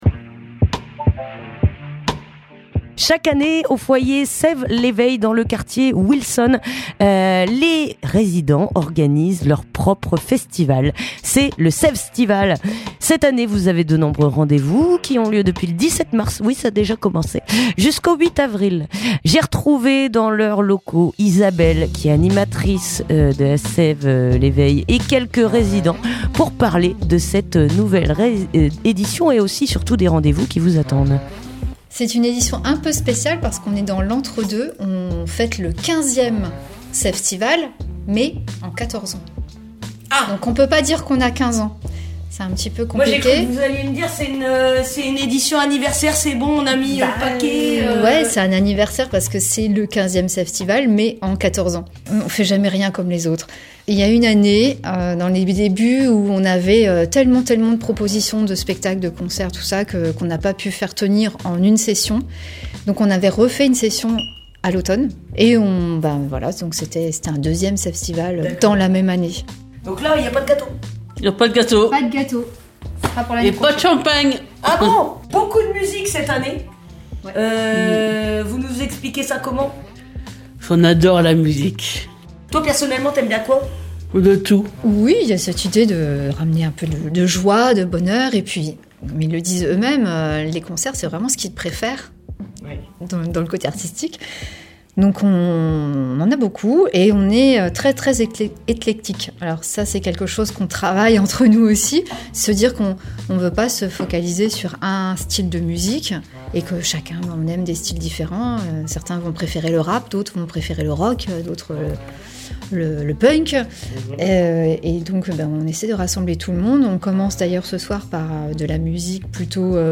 Reportage à Sève l'Eveil (14:54)